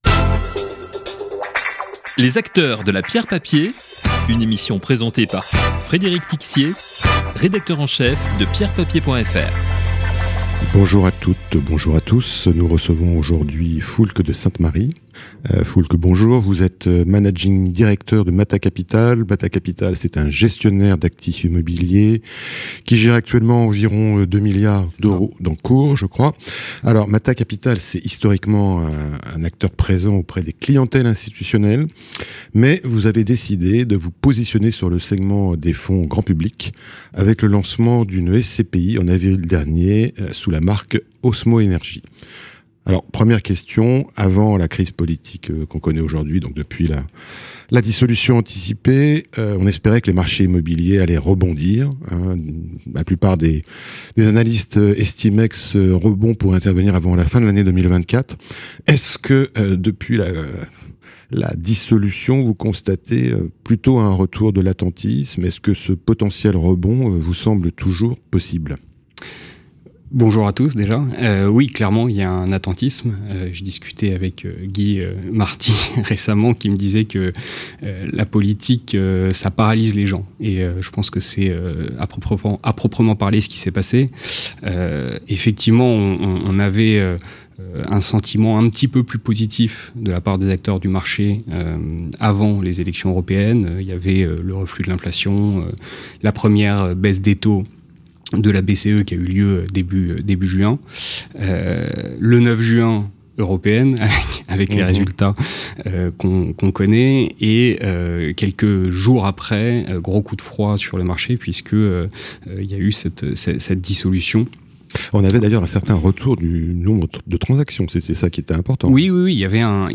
Podcast d'expert
Interview.